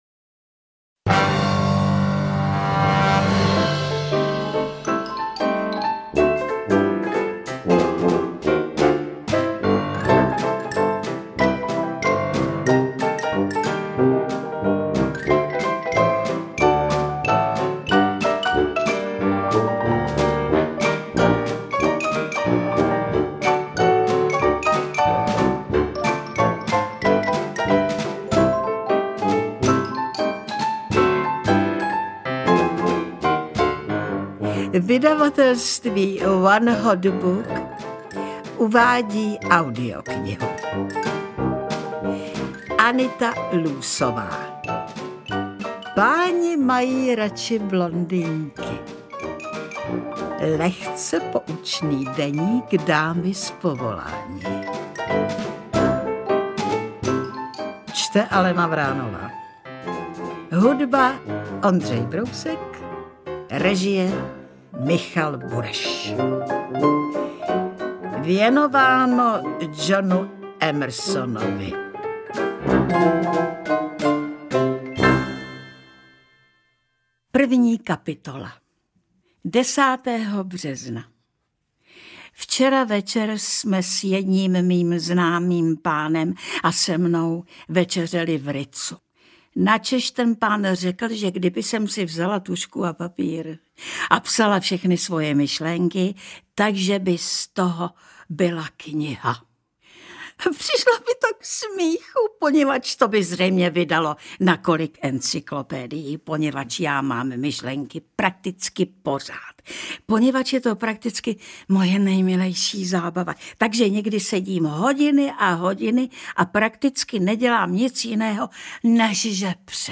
Interpret:  Alena Vránová
AudioKniha ke stažení, 17 x mp3, délka 5 hod. 16 min., velikost 289,7 MB, česky